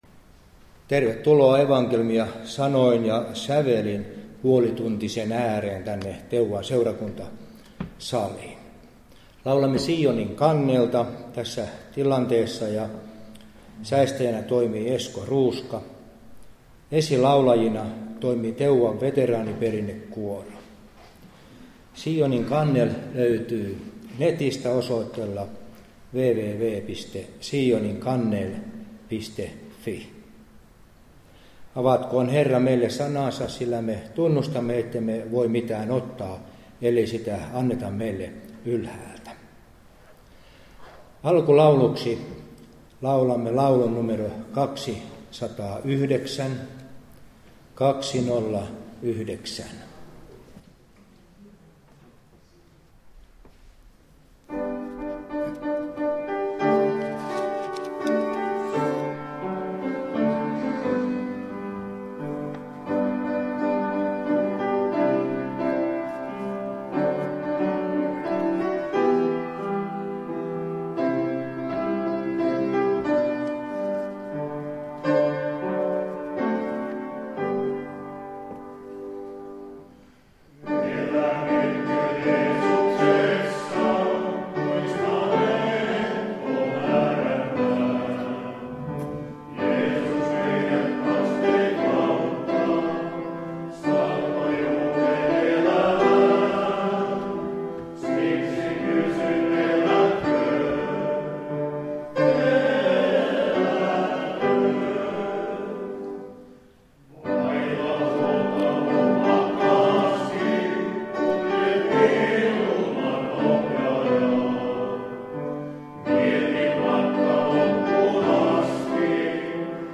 315 Teuvan srk-sali
Teuvan Veteraaniperinnekuoro